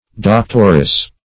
Doctoress \Doc"tor*ess\, n. A female doctor.